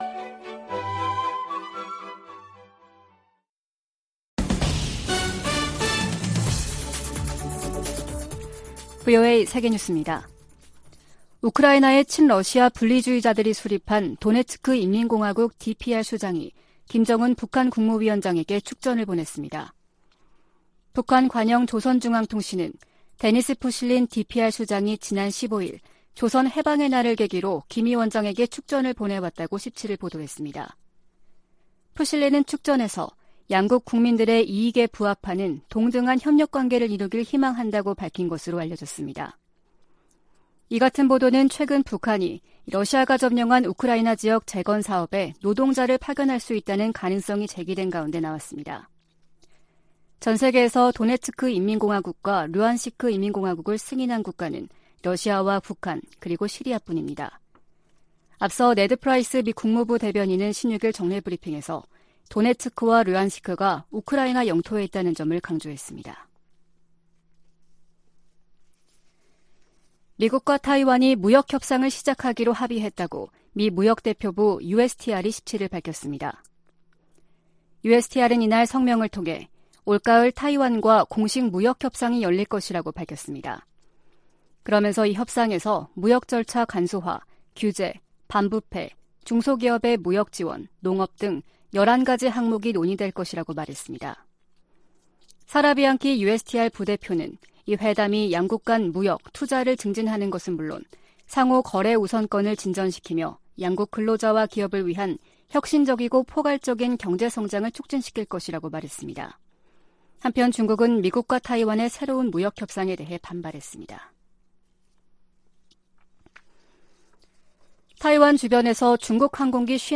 VOA 한국어 아침 뉴스 프로그램 '워싱턴 뉴스 광장' 2022년 8월 19일 방송입니다. 한국 정부가 ‘담대한 구상’과 관련한 구체적인 대북 메시지를 발신하고 북한이 수용할 수 있는 여건을 조성해나갈 것이라고 밝혔습니다.